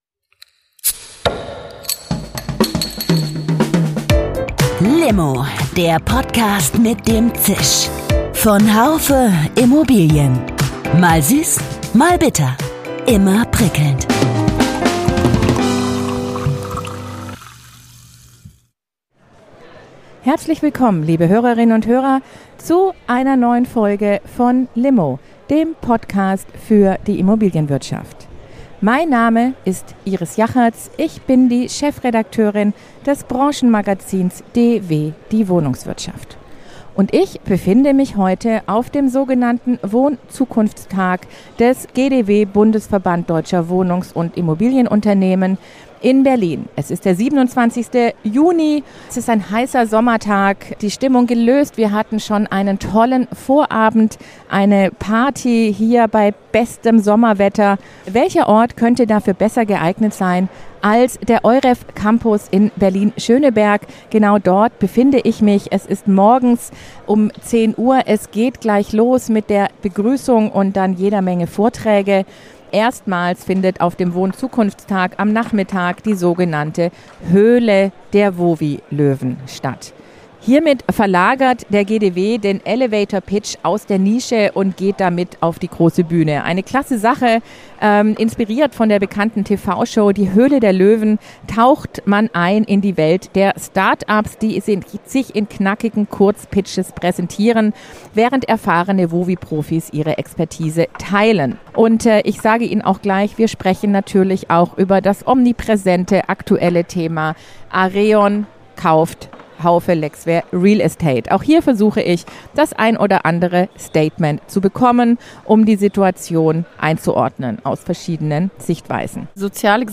Digitalisierung und Veränderungen ziehen sich als roter Faden durch diese L’Immo-Folge. Auf dem WohnZukunftsTag (WZT) des GdW Bundesverband deutscher Wohnungs- und Immobilienunternehmen e.V. tummelten sich am 27. Juni 2024 zahlreiche Vertreter und Partner der Wohnungswirtschaft – an Gesprächsstoff mangelte es wahrlich nicht.
Passen Private Equity und die sozial orientierte Wohnungswirtschaft zusammen? Dazu kommen der Verband, ein Wohnungsunternehmen und PropTechs zu Wort.